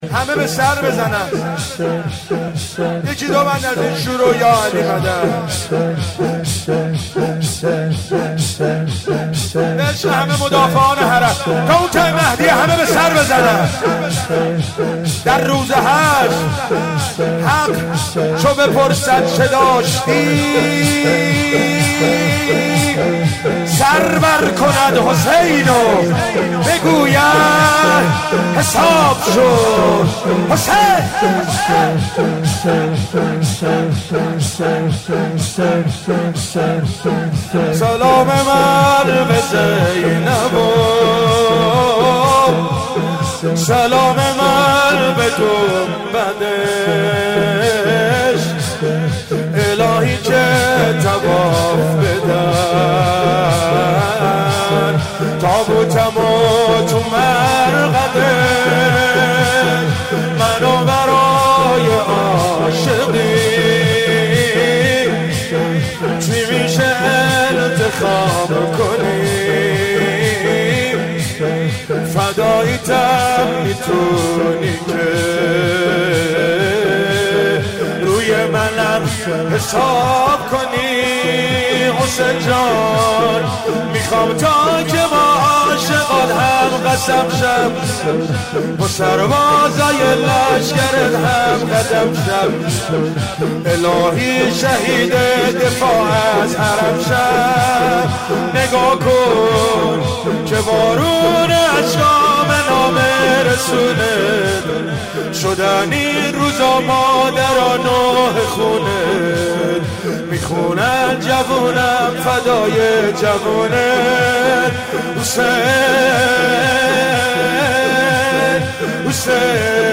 شور مداحی